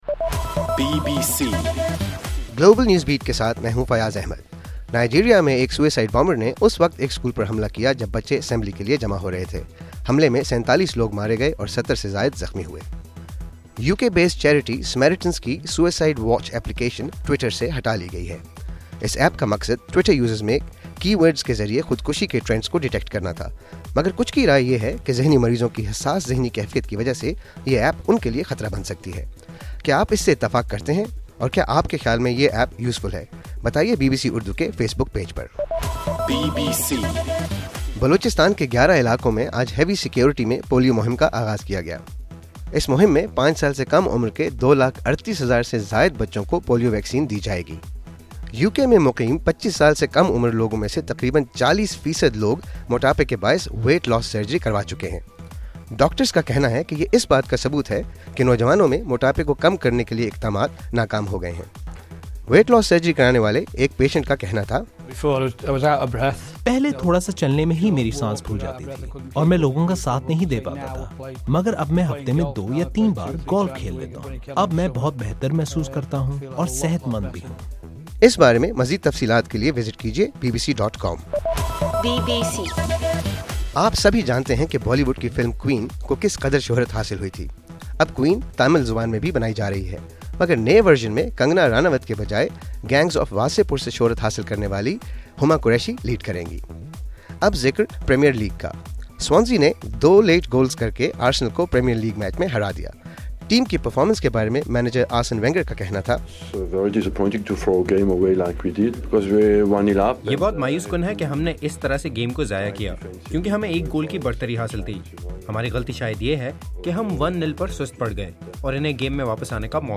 نومبر 10: رات 8 بجے کا گلوبل نیوز بیٹ بُلیٹن